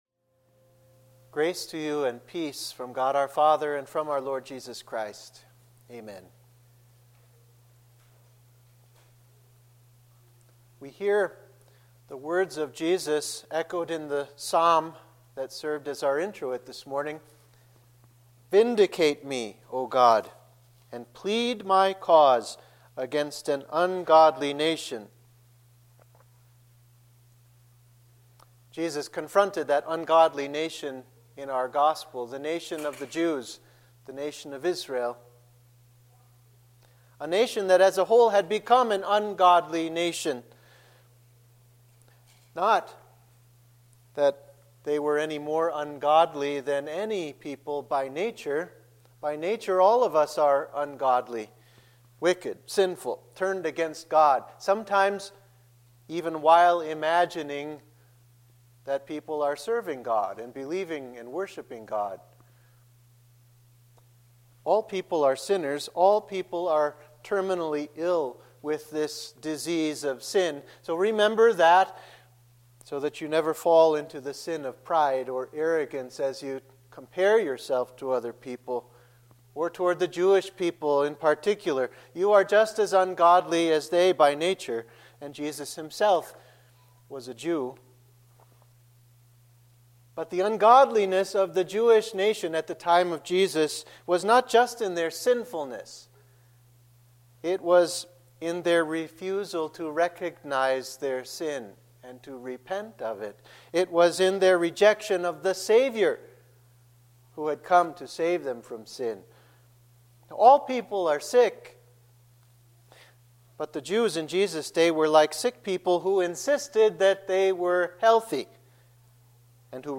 Sermon for Judica – Fifth Sunday in Lent 2014